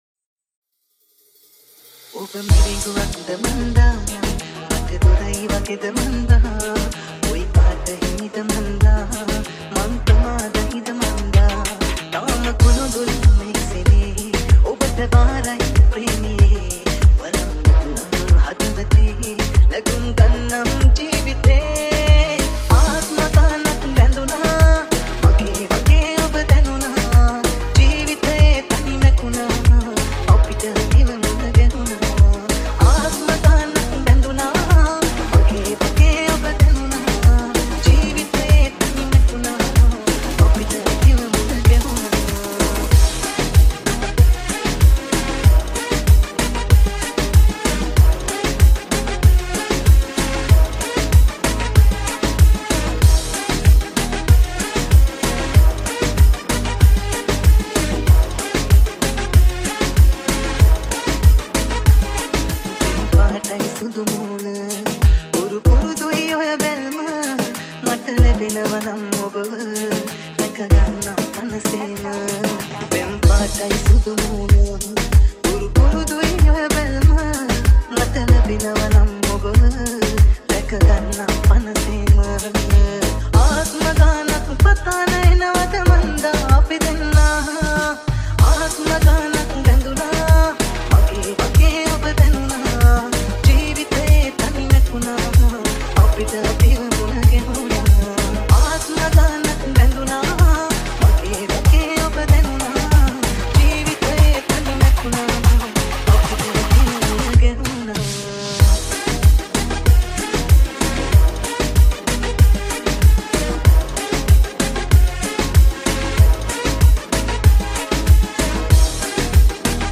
Sinhala Remix | Sinhala DJ Songs